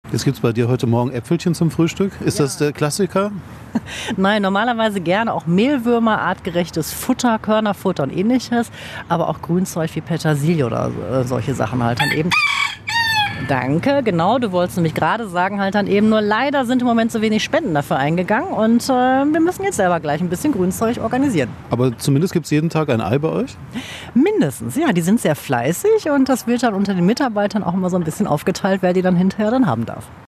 Wir haben die Truppe in ihrem Gehege besucht und erfahren, warum Konstantin hier gelandet ist und was er in den Osterferien zutun hat.
huhn.mp3